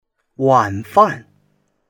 wan3fan4.mp3